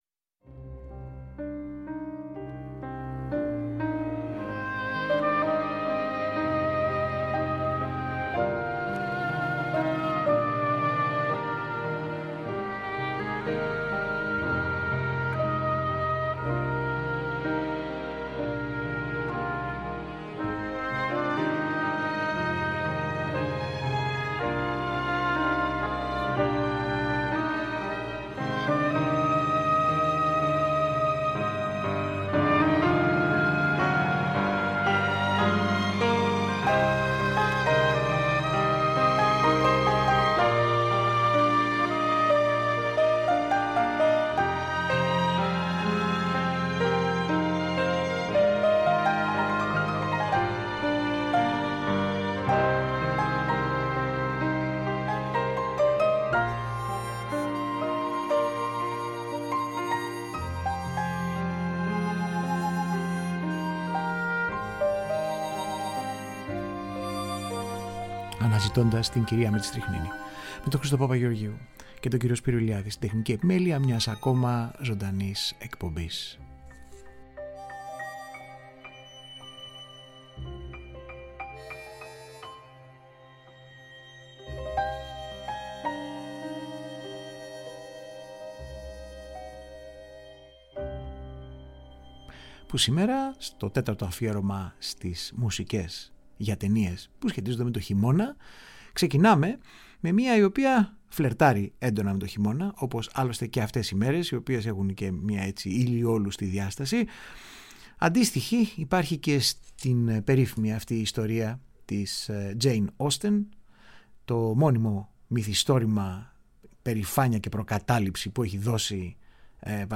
SOUNDTRACKS Κινηματογραφικη Μουσικη